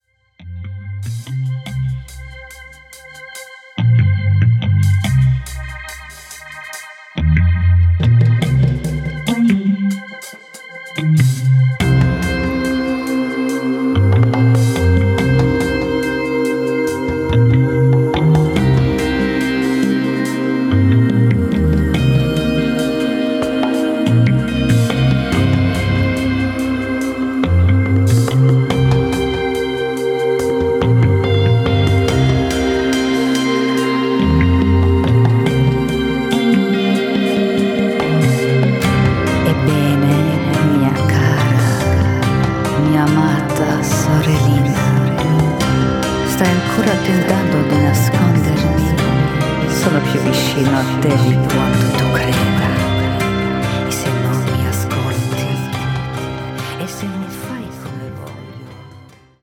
auf eine atmosphärische, psychedelische Grundstimmung